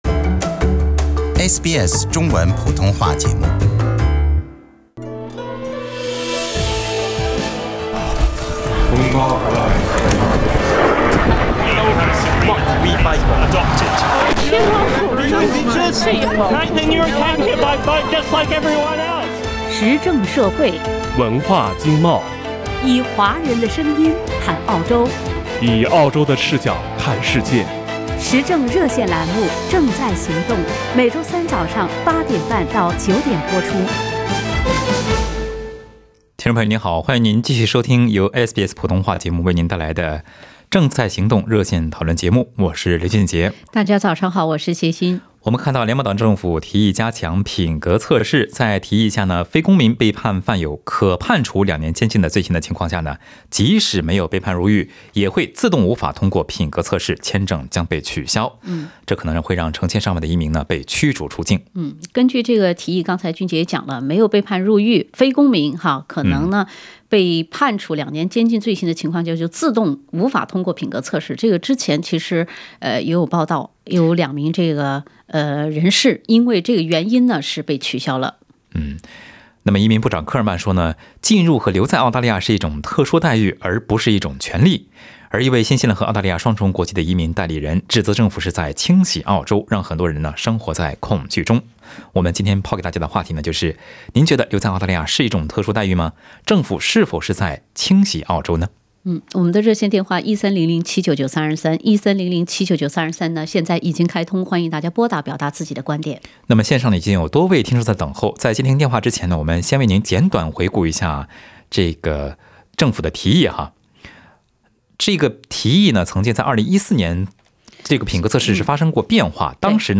听众朋友在本期《正在行动》热线节目中表达了自己的观点。